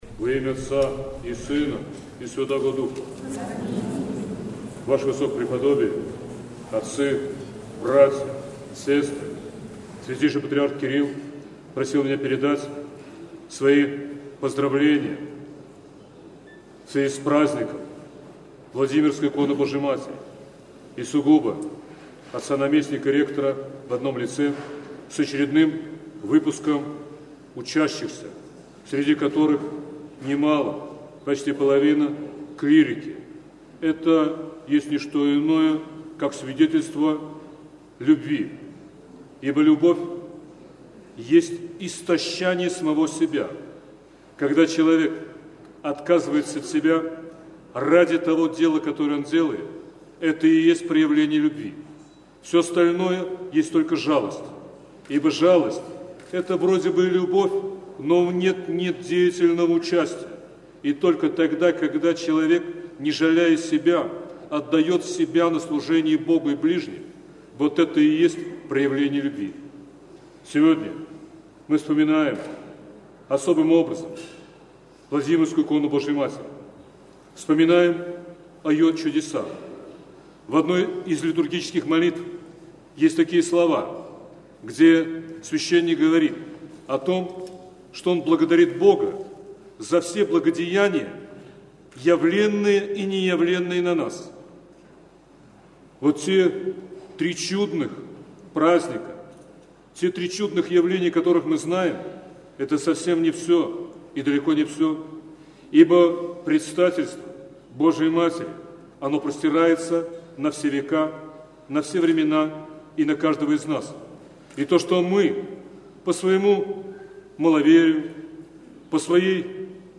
Сретенский монастырь.
Слово, произнесенное архиепископом Сергиево-Посадским Феогностом по окончании Литургии